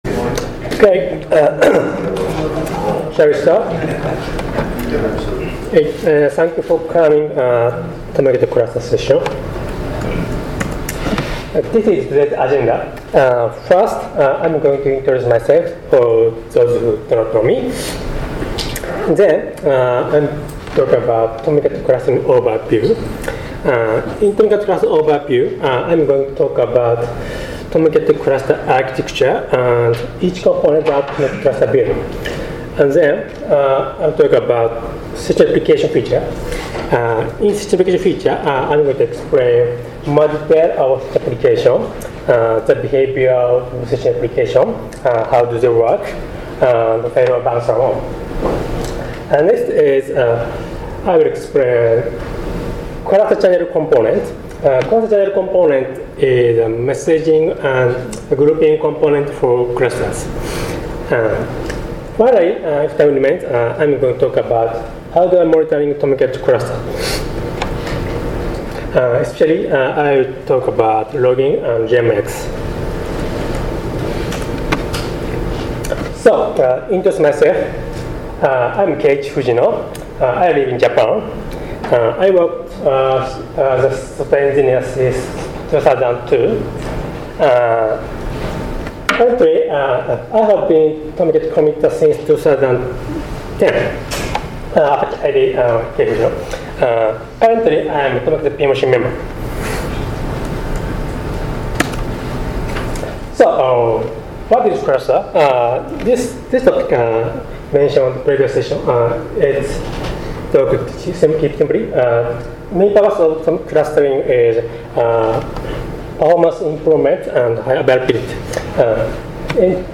ApacheCon Miami 2017